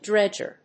音節drédg・er 発音記号・読み方
/ˈdɹɛdʒ.ə(ɹ)(英国英語)/